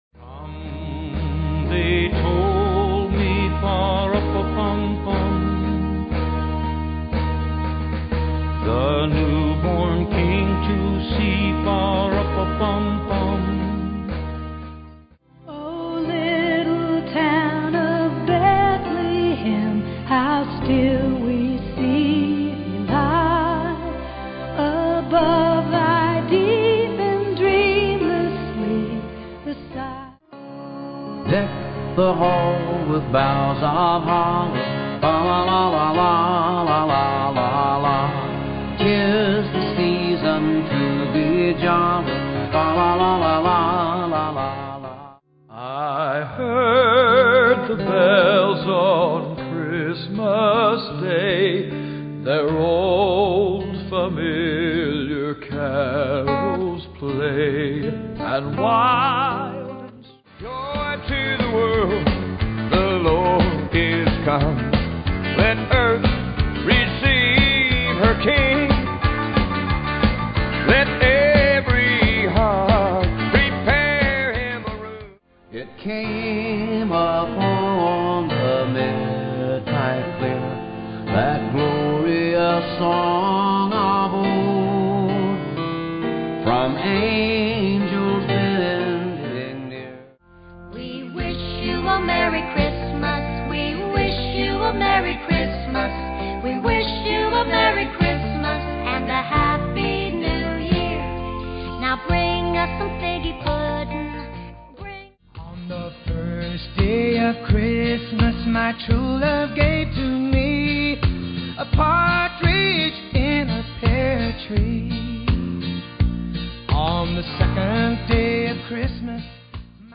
Christmas with a country feel. Vocals.